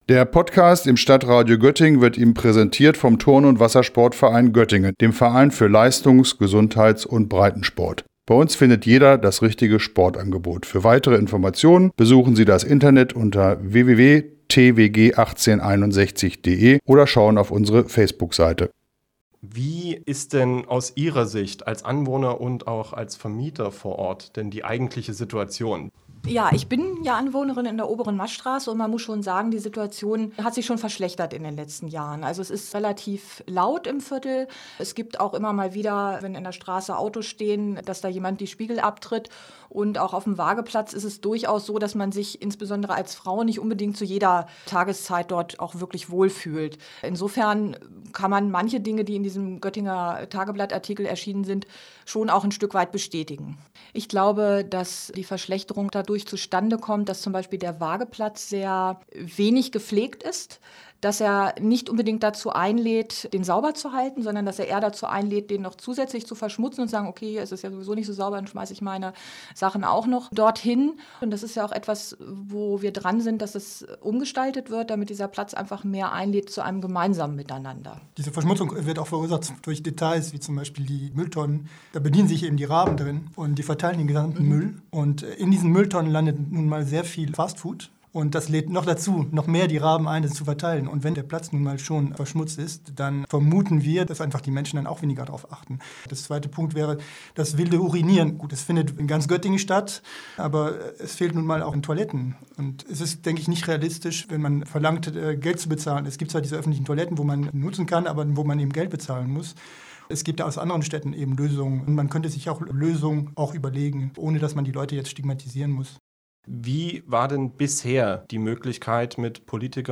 hat aus diesem Anlass mit dort lebenden Göttingern gesprochen, die sich im Bürgerforum Waageplatz engagieren.